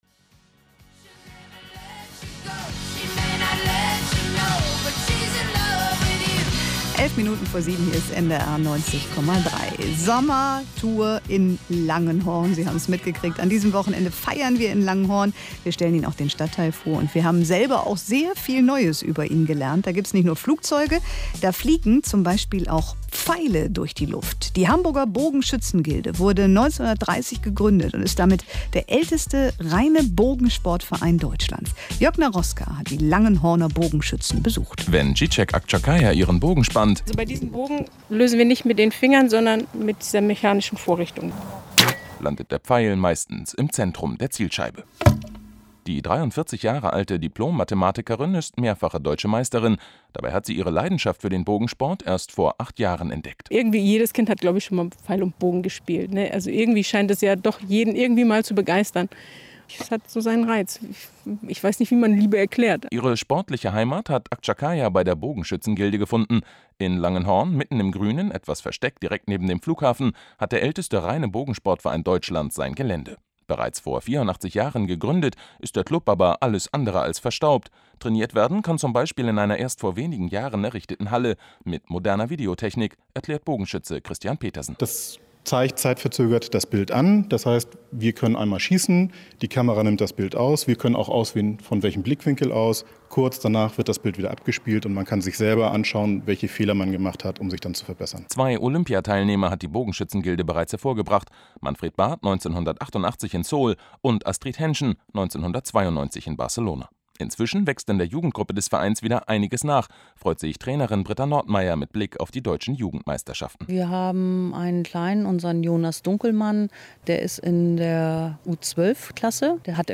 Wir im Radio